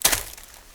HauntedBloodlines/STEPS Leaves, Walk 18.wav at 545eca8660d2c2e22b6407fd85aed6f5aa47d605 - HauntedBloodlines - Gitea: Git with a cup of tea
STEPS Leaves, Walk 18.wav